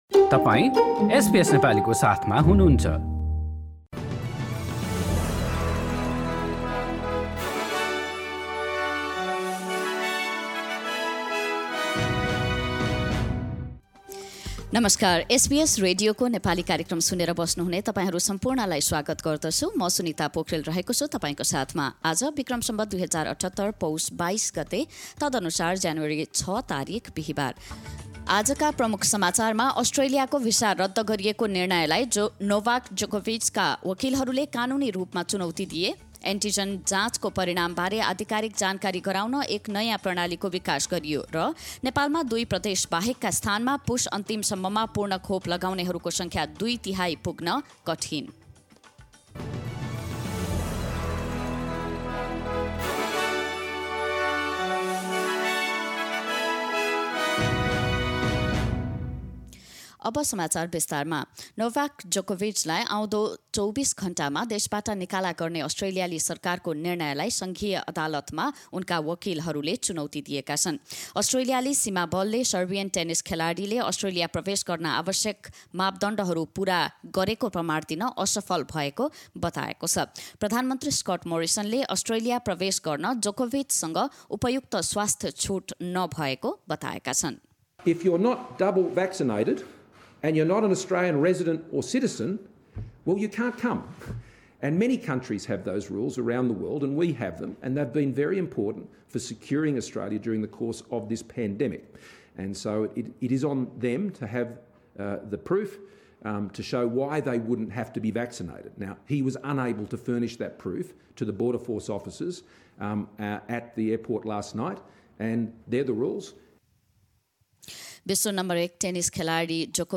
एसबीएस नेपाली अस्ट्रेलिया समाचार: बिहीबार ६ जनवरी २०२२